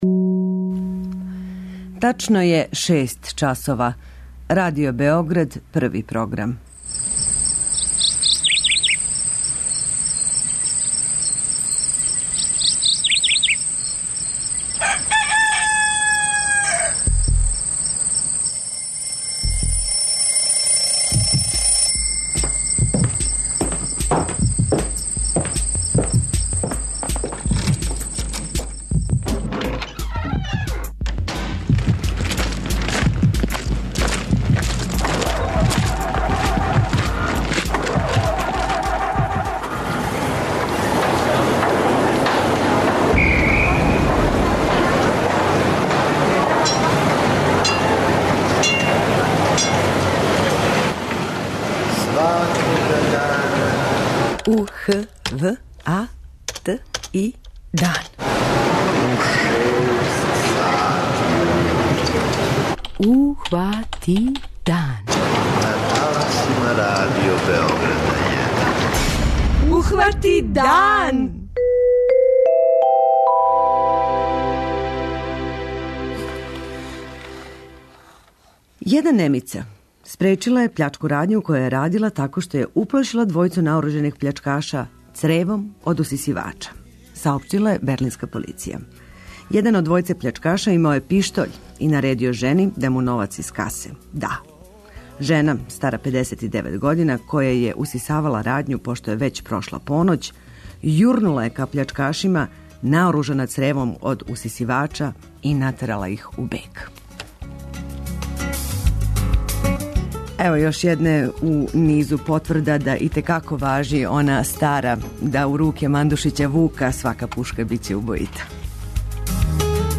преузми : 85.96 MB Ухвати дан Autor: Група аутора Јутарњи програм Радио Београда 1!